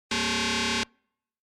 Download Free Futuristic Alarm Sound Effects | Gfx Sounds
Sci-fi-alarm-strong-dramatic-single-alert.mp3